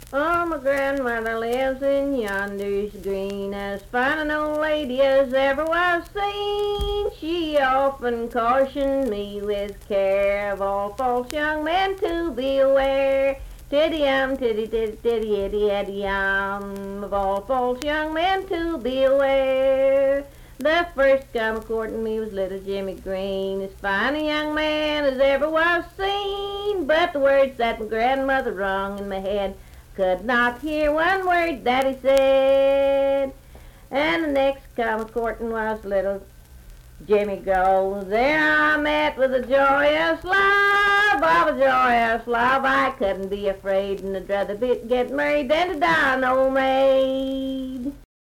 Unaccompanied vocal music
Verse-refrain 3(4).
Voice (sung)
Richwood (W. Va.), Nicholas County (W. Va.)